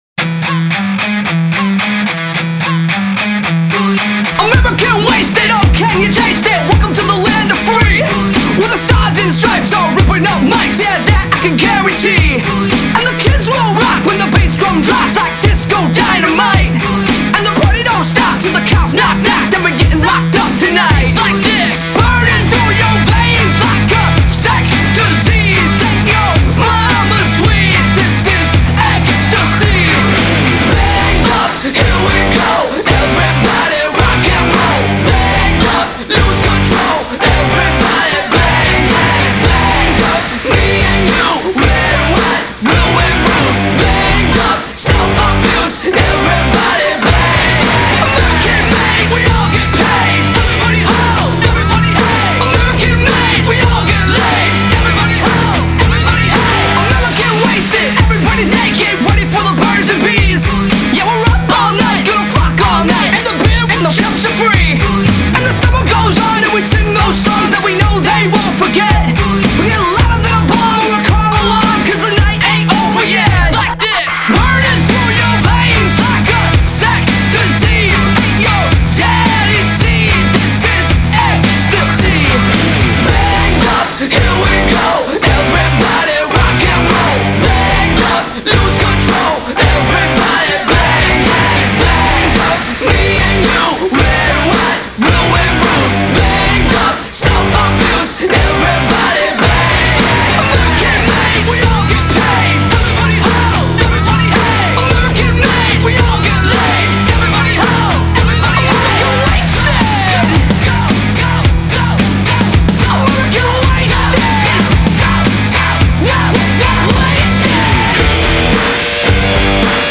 Rock 2010er